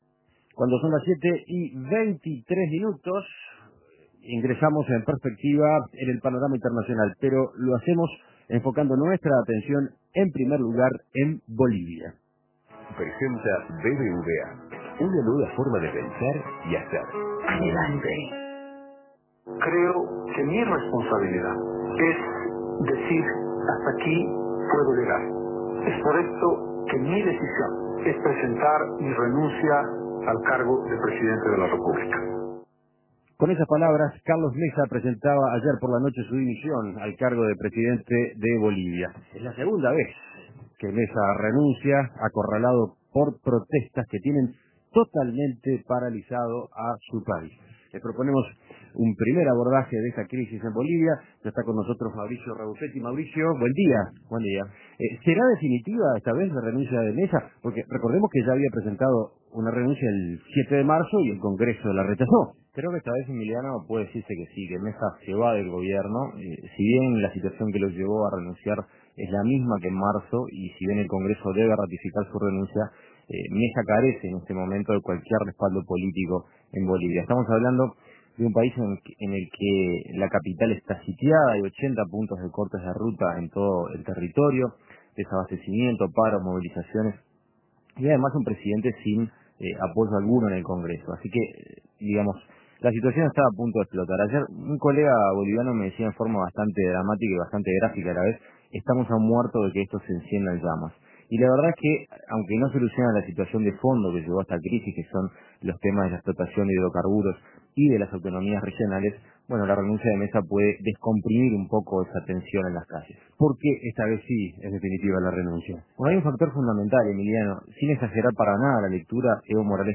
Comentario del periodista